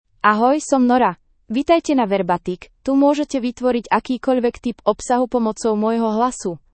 NoraFemale Slovak AI voice
Nora is a female AI voice for Slovak (Slovakia).
Voice sample
Listen to Nora's female Slovak voice.
Female
Nora delivers clear pronunciation with authentic Slovakia Slovak intonation, making your content sound professionally produced.